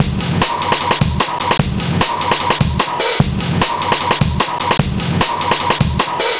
Index of /90_sSampleCDs/Zero-G - Total Drum Bass/Drumloops - 1/track 01 (150bpm)